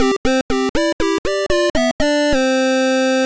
Level_Up.ogg